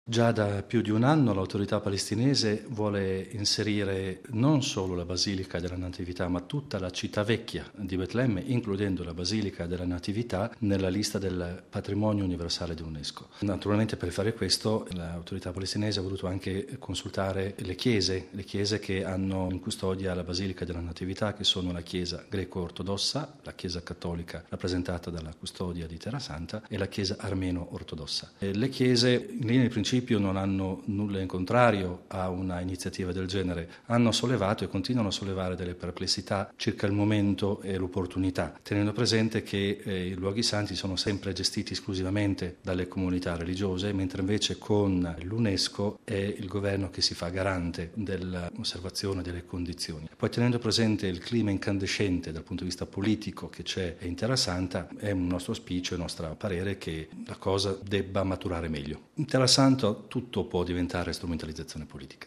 ascoltiamo padre Pierbattista Pizzaballa, custode di Terra Santa: